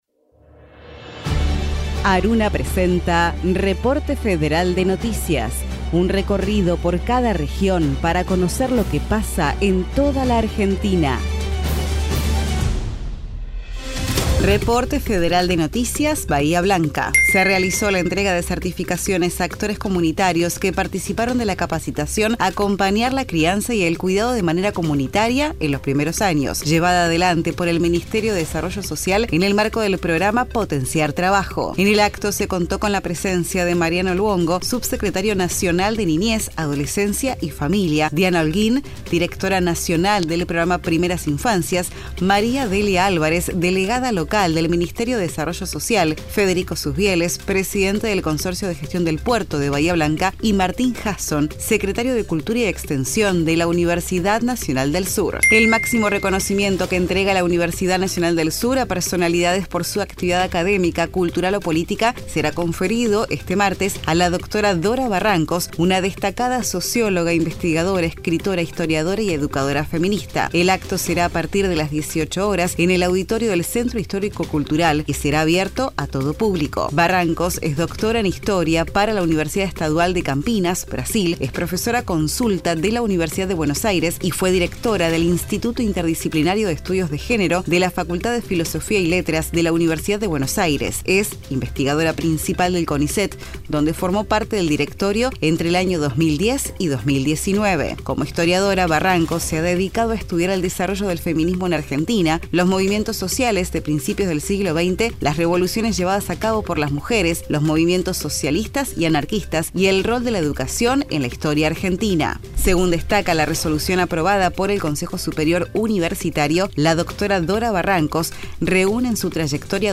Reporte Federal Texto de la nota: Radio UNDAV - Reporte Federal de noticias Producción colaborativa de ARUNA Las emisoras de universidades nacionales que integran la Asociación de Radios Universitarias Nacionales Argentinas (ARUNA) emiten un informe diario destinado a brindar información federal con la agenda periodística más destacada e importante del día. Un programa que contiene dos noticias por cada radio participante, una noticia institucional de las universidades nacionales y otra local o provincial de interés social, con testimonios de las y los protagonistas locales.